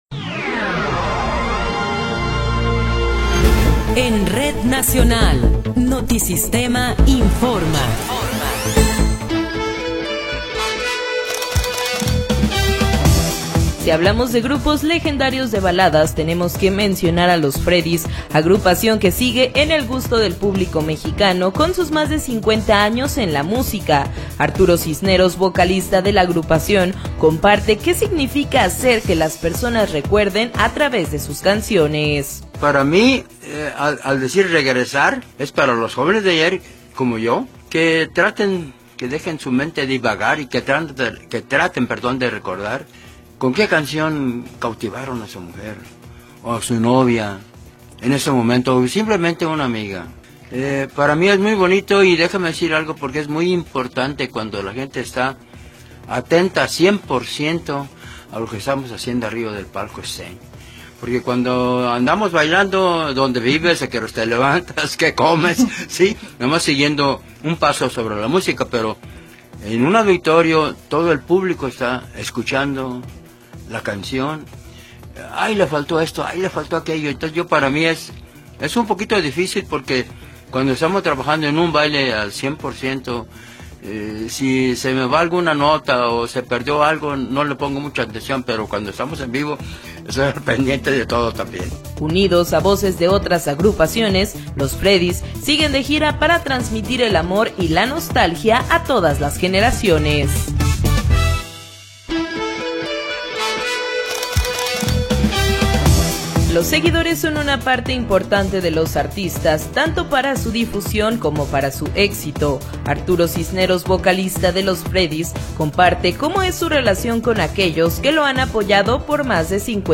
Noticiero 19 hrs. – 1 de Marzo de 2026
Resumen informativo Notisistema, la mejor y más completa información cada hora en la hora.